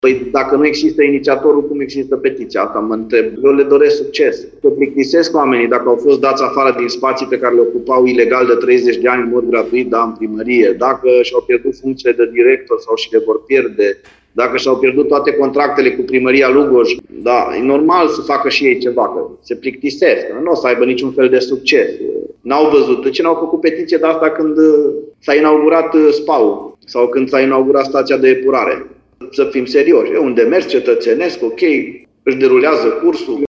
În replica, primarul Claudiu Buciu spune că respectiva asociație nu există și că de fapt este vorba de o organizație similară, care a fost evacuată din sediul Primăriei.